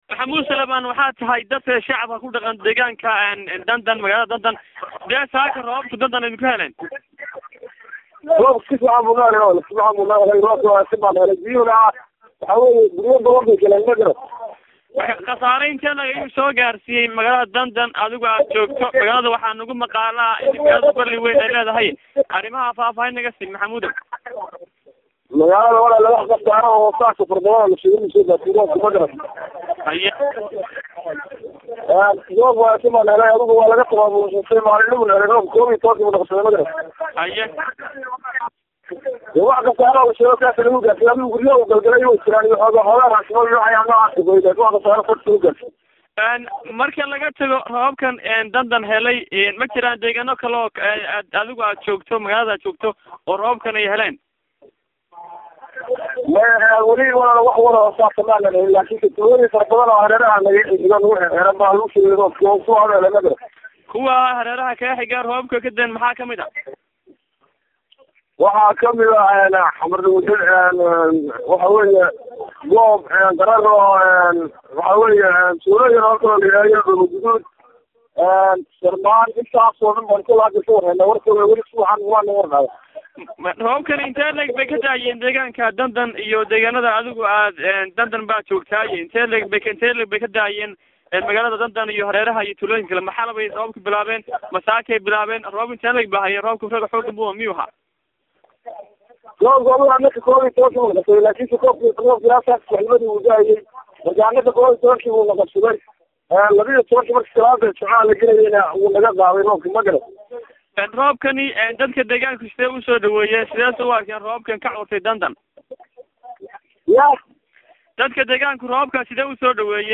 Shabakada wararka ee Ceegaag online ayaa taleefoonka kula xidhiidhay magaalooyina Xamar lagu xidh, Sool joogtoi, Dandan xadhadhanka Dhilaalo iyo magaalooyina kale oo roobab xoog badani habeenimadii xalayo ilaa maanta galinkii hore siwayn u maansheeyey.